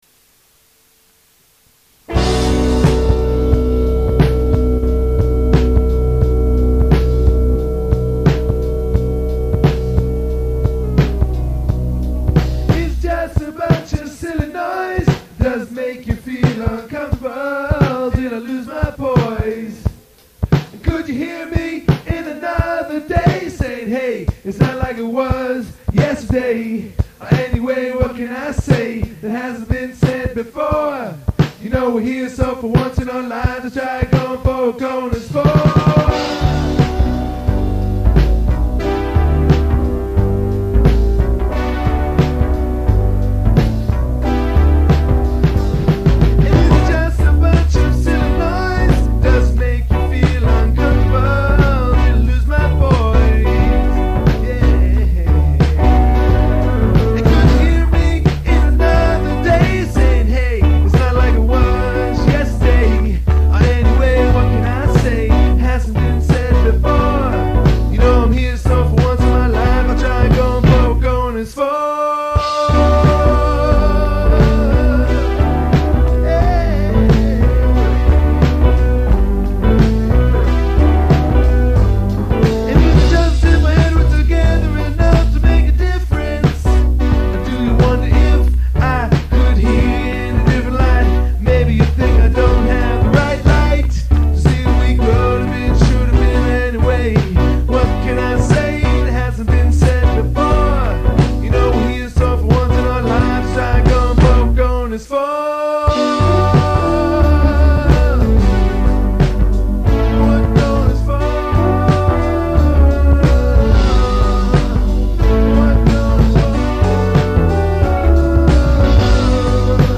recorded this funky version
guitars